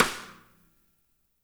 -MEDSNR2Q -L.wav